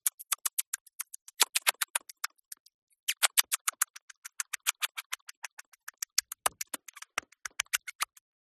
Odgłosy zwierząt wiejskich
Mysz
zapsplat_cartoon_mouse_eat_cheese_23609.mp3